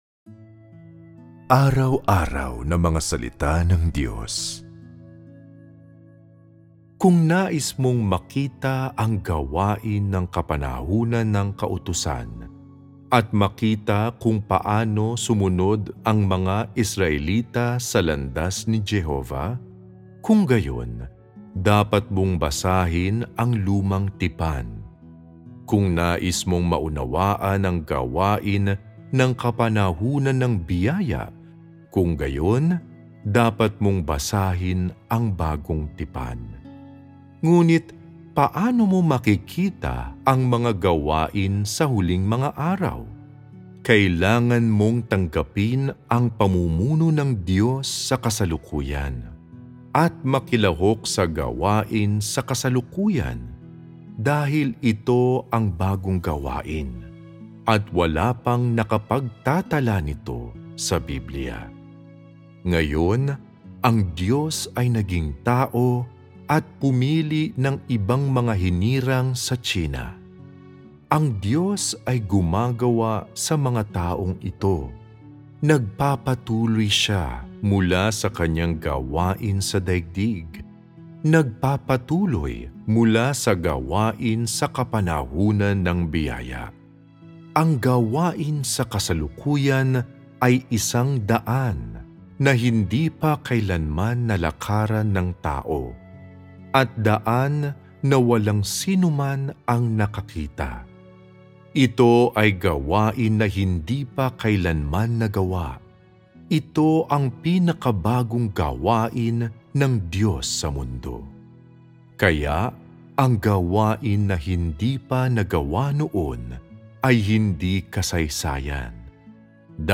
recital-mysteries-about-the-bible-268.m4a